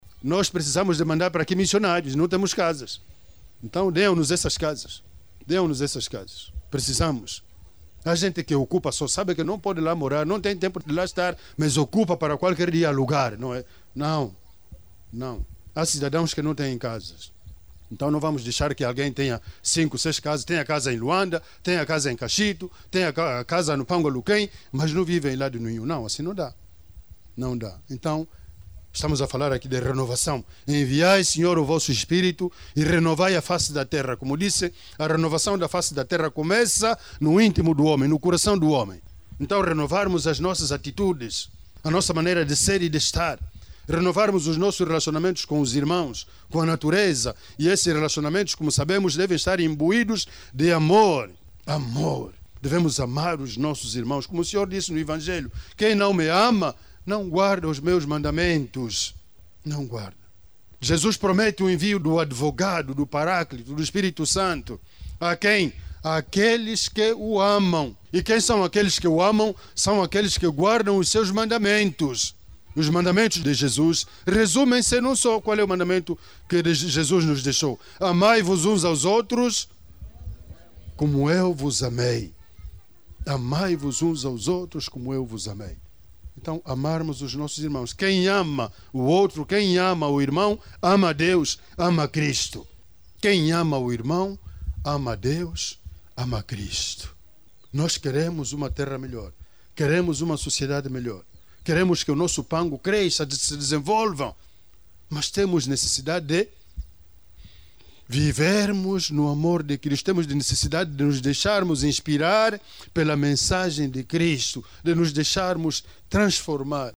Dom Maurício Camuto falava durante a homilia da missa deste domingo, dia de Pentecostes no Pango Aluquém -onde ressaltou o amor para que se tenha uma sociedade melhor.